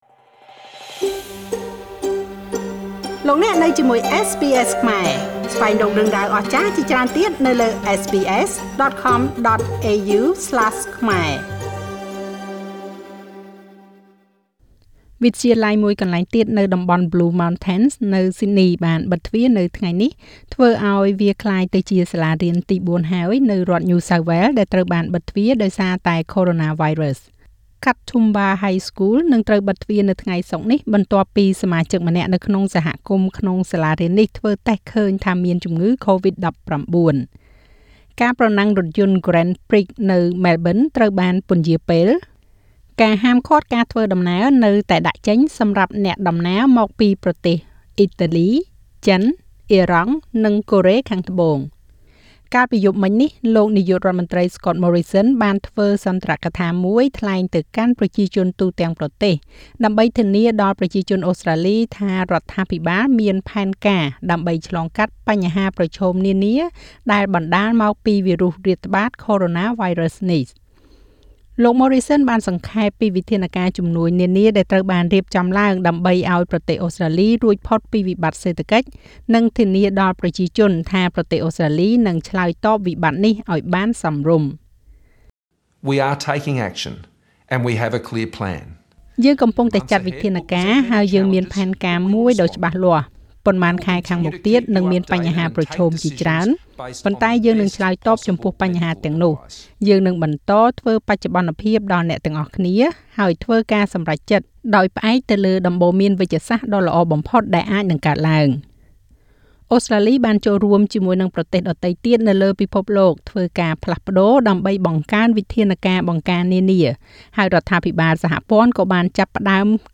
នាយករដ្ឋមន្រ្តីអូស្រ្តាលីធ្វើសុន្ទរកថាទៅកាន់ប្រជាជនទូទាំងប្រទេសស្តីពី ផែនការរបស់រដ្ឋាភិបាលទប់ទល់នឹងCovid-19
Scott Morrison addressing the nation on Thursday night Source: SBS News